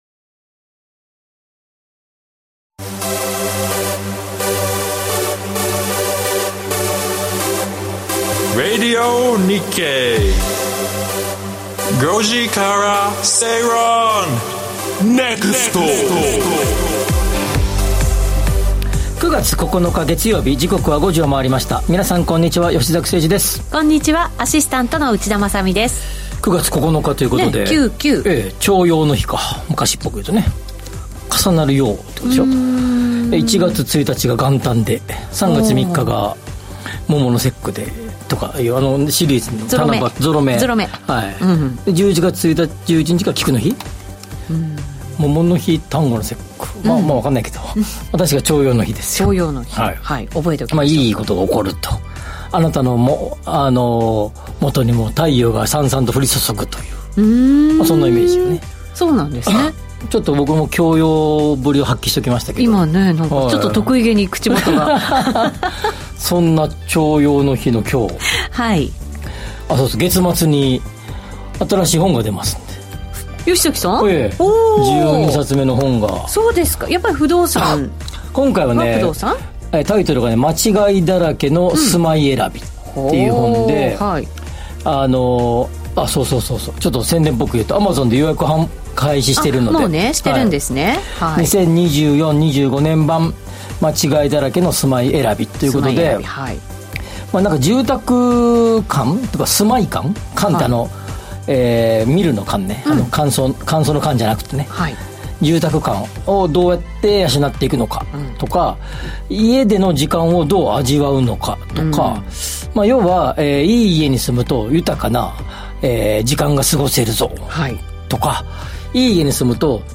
ビジネスマンや個人投資家に向けて 「景気・経済動向をわかりやすく解説する月曜日」 明日の仕事でつかえるネタ、今夜の食事時に話したいネタを、人生を豊かにするネタをざっくばらんな雰囲気でお届けします！